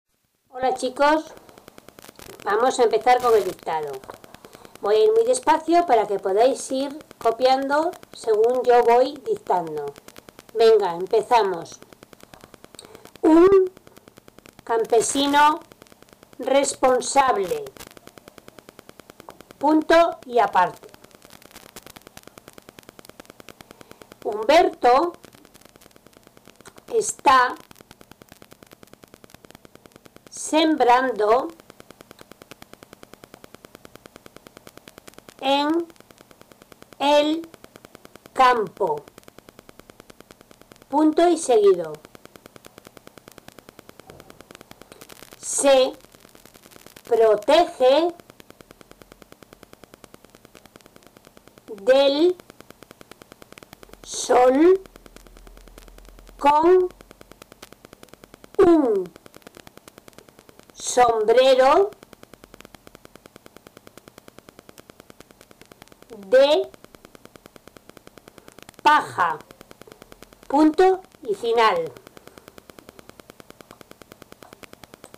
Dictado pág. 212
Dictado_Pag_212.mp3